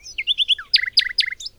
birds03.wav